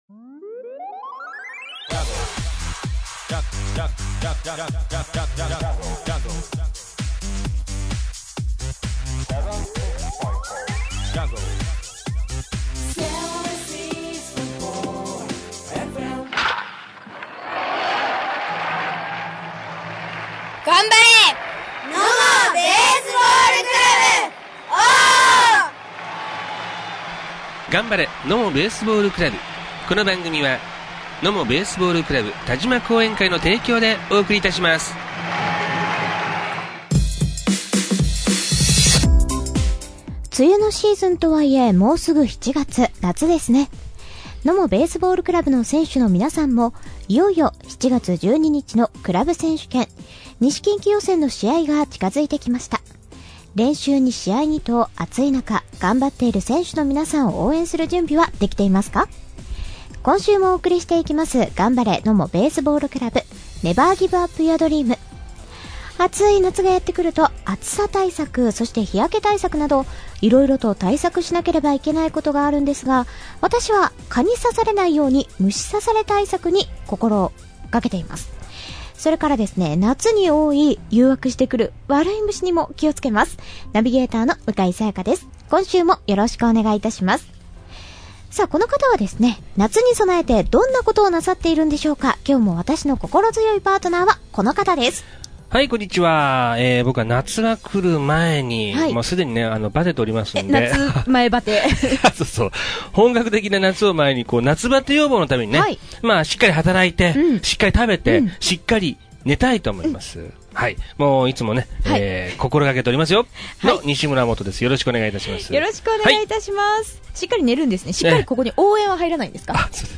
今イケイケなNOMOベースボールクラブの選手にインタビューしちゃいます！